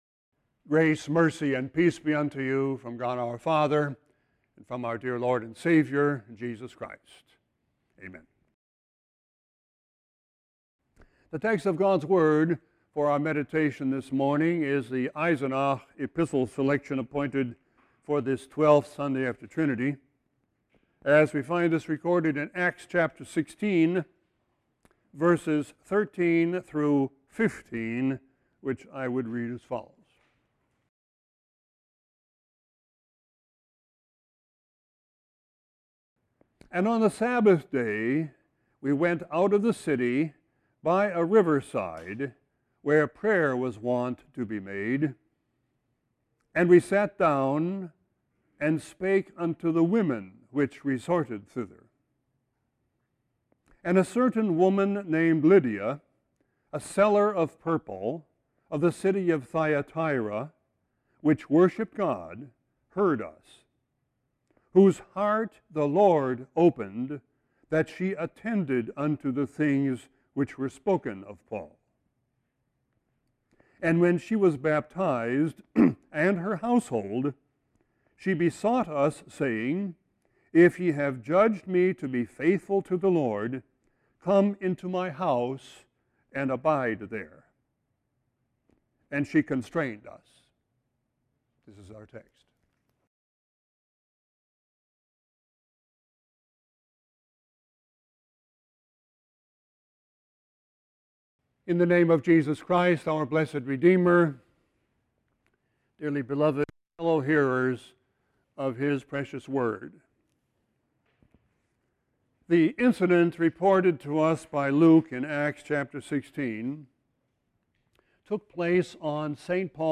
Sermon 8-14-16.mp3